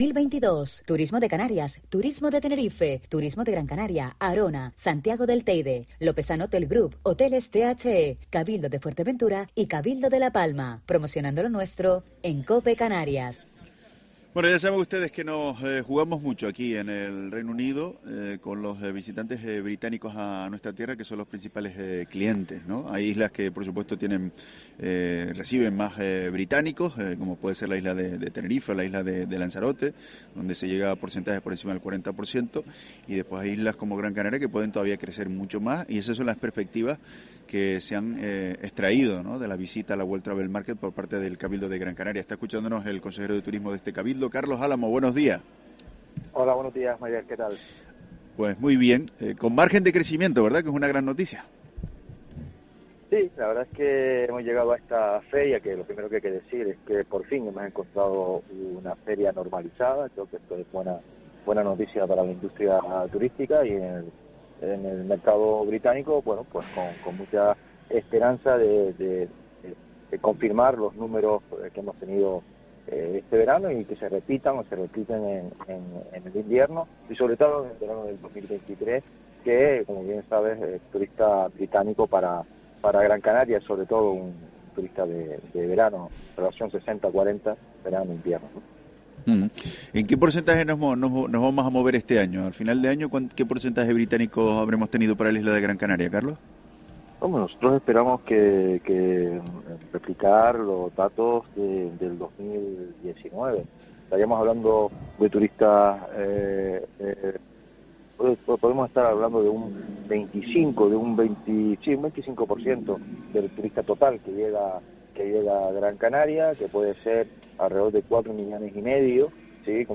Carlos Álamo, consejero de Turismo del cabildo de Gran Canaria y presidente del Patronato de Turismo
En el programa especial de 'La Mañana COPE Canarias' en la World Travel Market Hemos hablado con Carlos Álamo, consejero de Turismo del cabildo de Gran Canaria y presidente del Patronato de Turismo, asegura que “han llegado a esta feria con expectativas positivas, que nos la hemos encontrado normalizada y eso es buena noticia para la industria turística”.